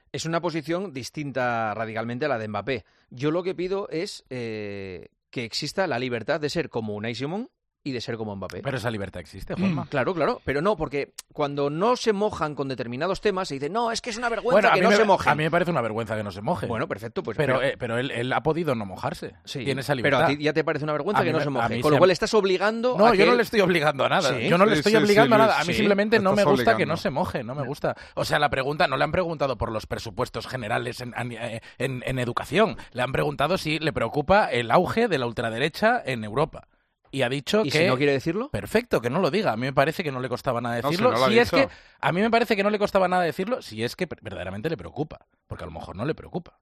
Acalorado debate entre Juanma Castaño y Luis García sobre la libertad de opinión de los futbolistas